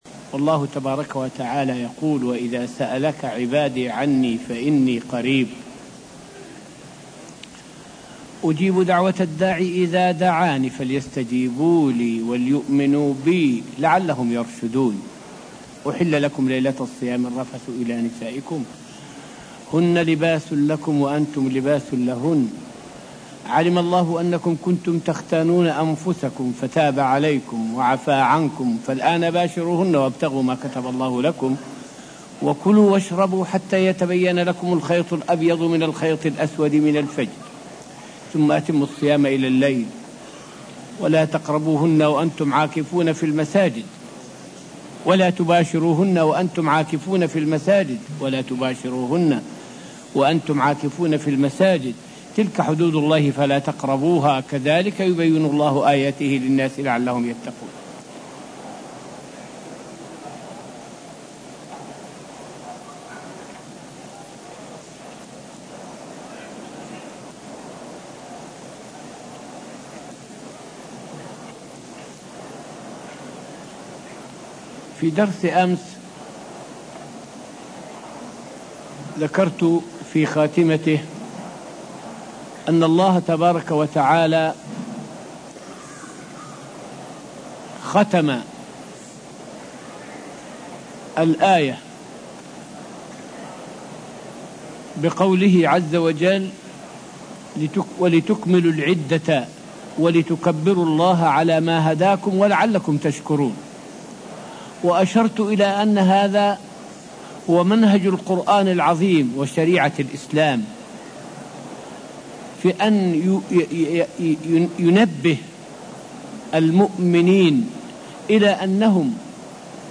فائدة من الدرس الثالث والعشرون من دروس تفسير سورة البقرة والتي ألقيت في المسجد النبوي الشريف حول الحكمة من ذكر الله بعد كل عبادة.